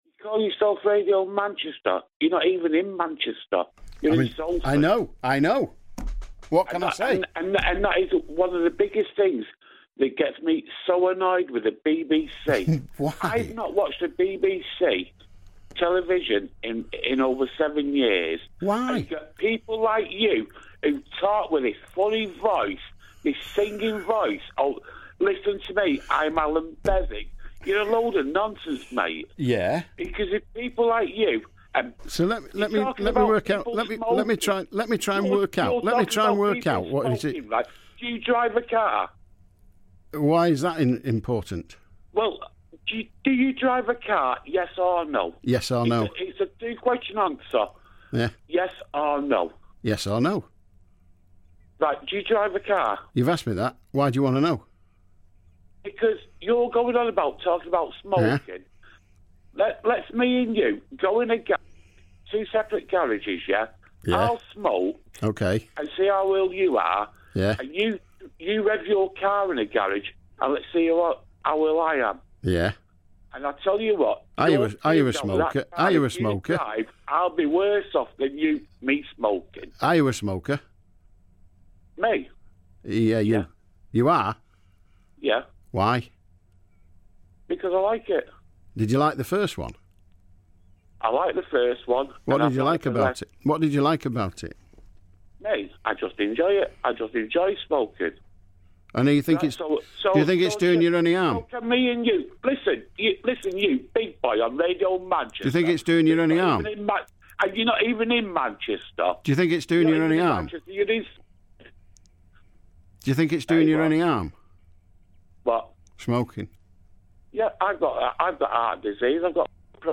Caller